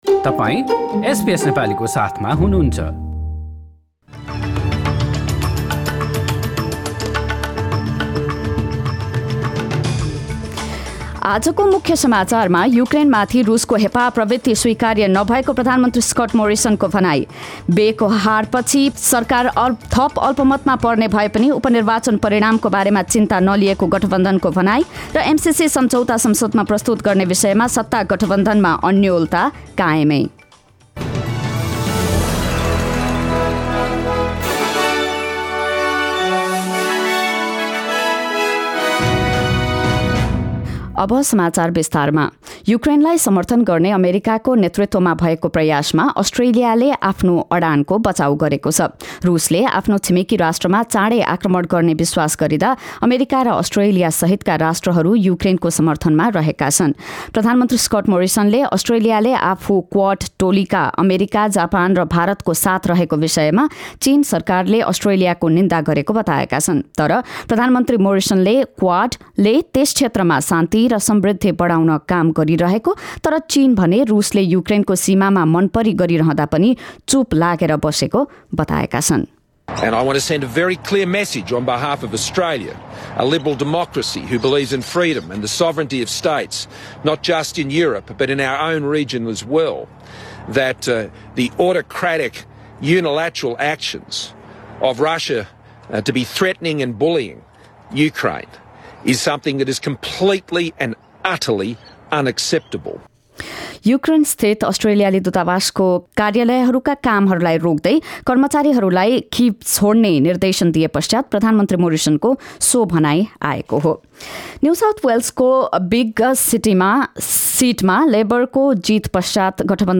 समाचार